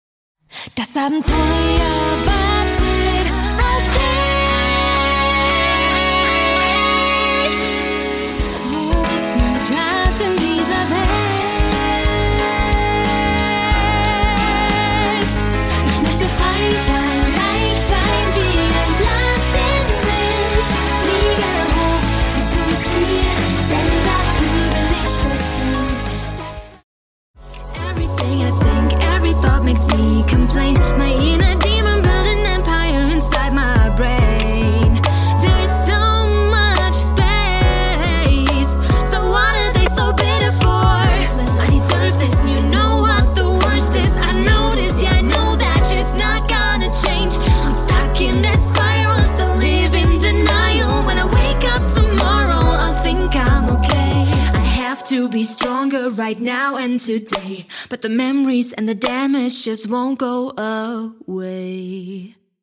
Kinderbuch